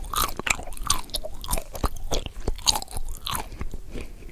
action_eat_3.ogg